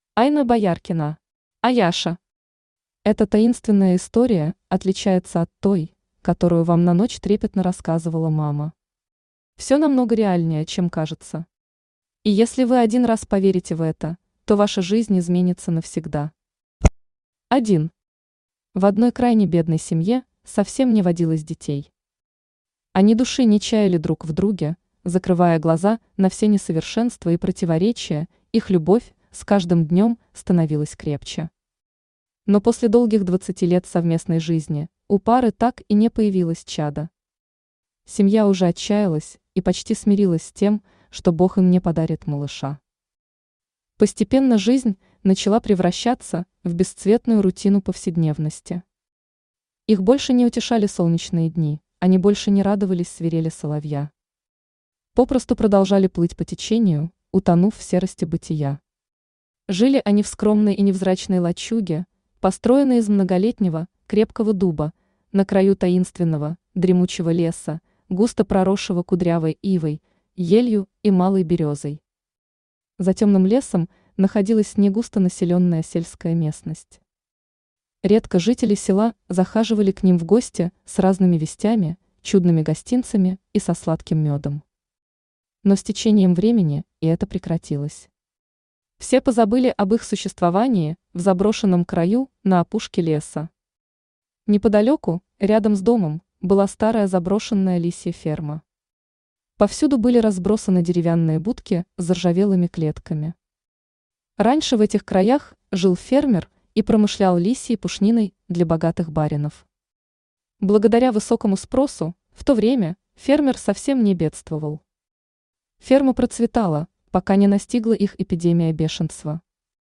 Аудиокнига Аяша | Библиотека аудиокниг
Читает аудиокнигу Авточтец ЛитРес.